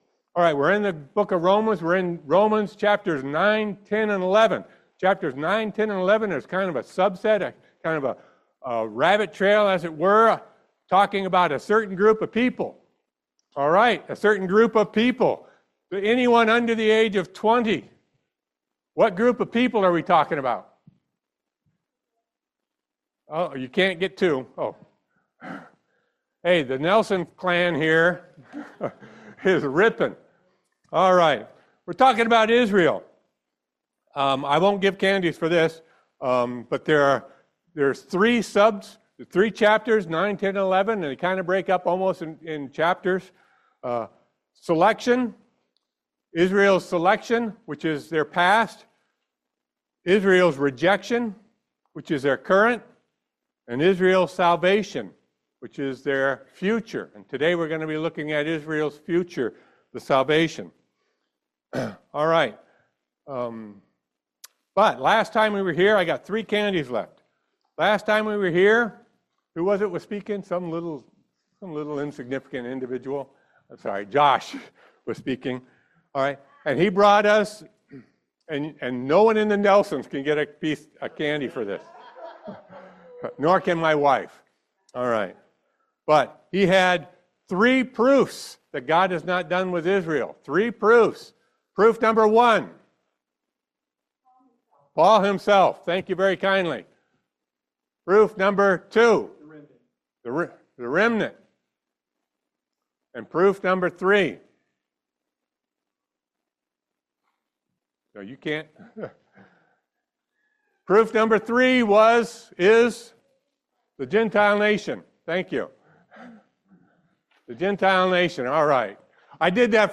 Romans 11:13-36 Service Type: Family Bible Hour God’s grace and faithfulness in Israel.